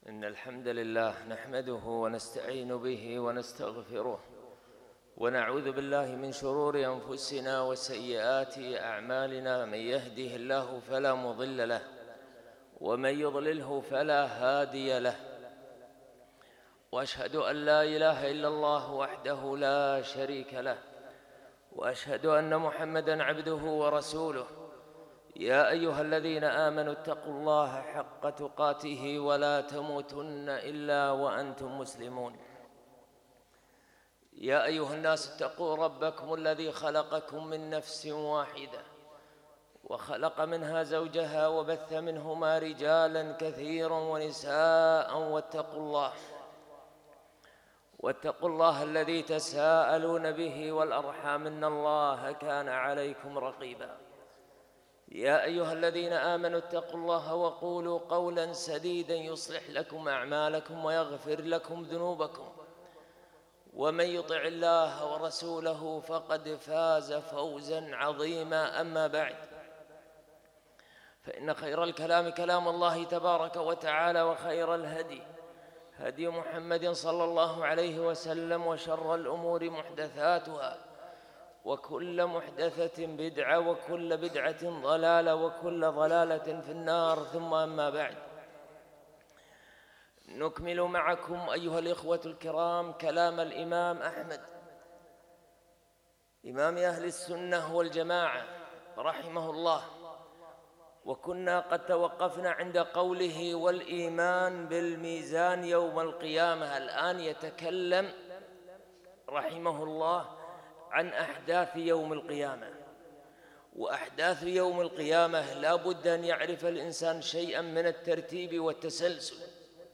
خطــب الجمــعة